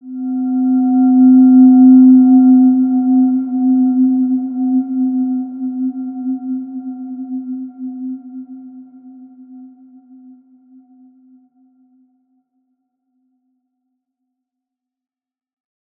Evolution-C4-p.wav